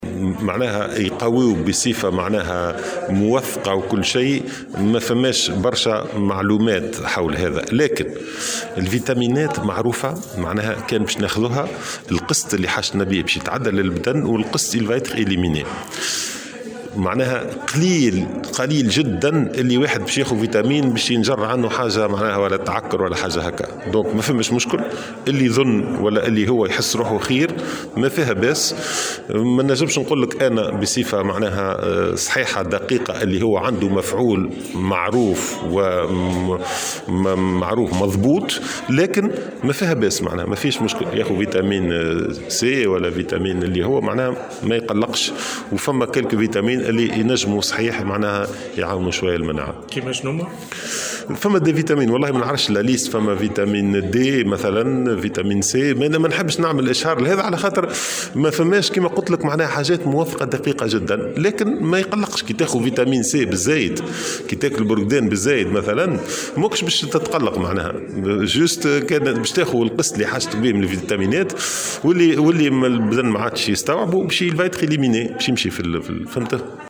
وأضاف في تصريح اليوم لمراسل "الجوهرة أف ام" على هامش ندوة صحفية عقدتها وزارة الصحة، أنه ما من خطر على الصحة في صورة تناول مثل هذه الفيتامينات التي بإمكان البعض منها على غرار الغنية بالفيتامين "c"و "D" المساعة على تعزيز المناعة.